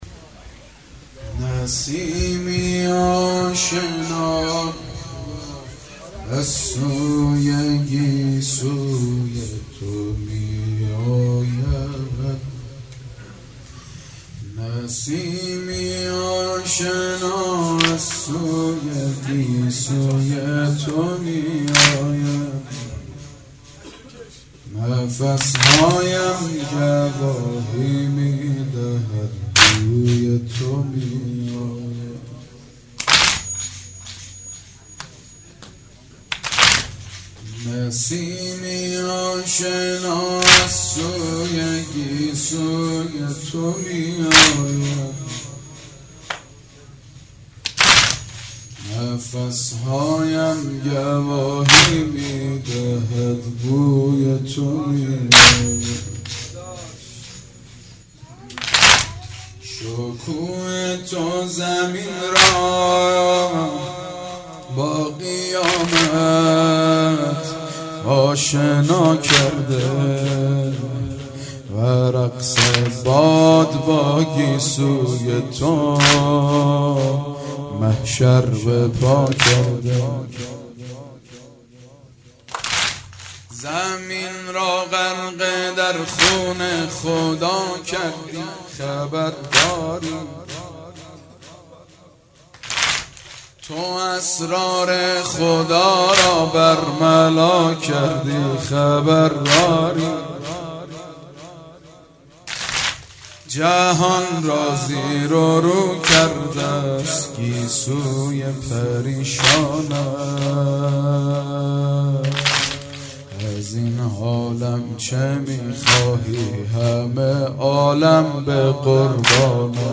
مدح امام حسن